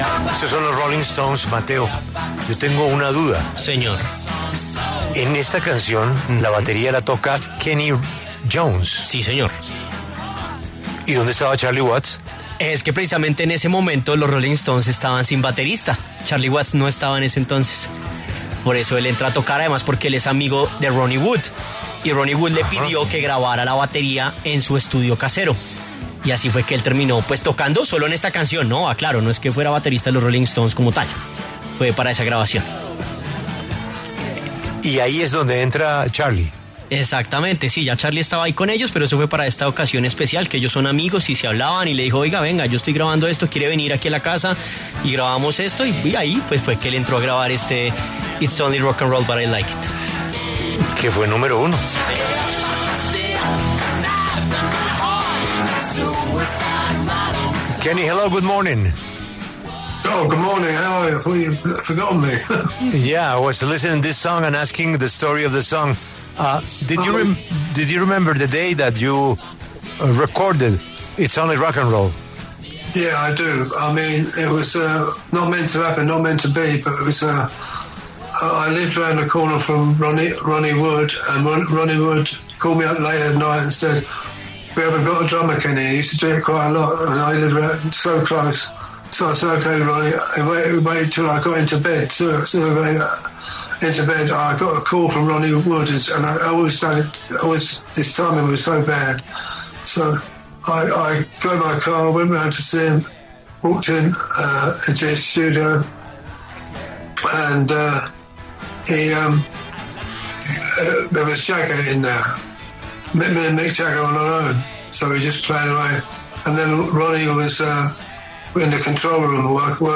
El famoso baterista británico Kenney Jones, pasó por los micrófonos de La W, con Julio Sánchez Cristo, para compartir experiencias de su carrera musical después de 60 años de haber comenzado con la banda Small Faces.